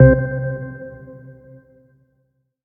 menu-exit-click.ogg